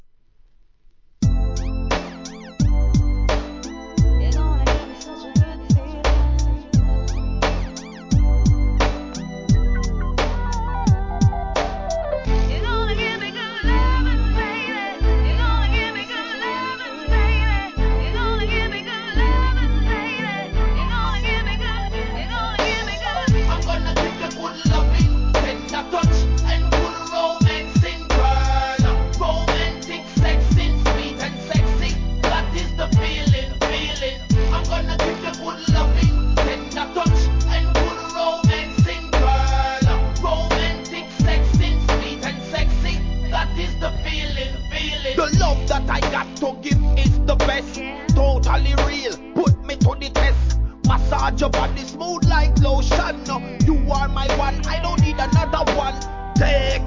G-RAP/WEST COAST/SOUTH
1997年、Gシンセが乗った哀愁レイドバックでラガ・フロウの人気激マイナー物!!